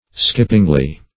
skippingly - definition of skippingly - synonyms, pronunciation, spelling from Free Dictionary Search Result for " skippingly" : The Collaborative International Dictionary of English v.0.48: Skippingly \Skip"ping*ly\, adv.